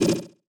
Message Bulletin Echo 10.wav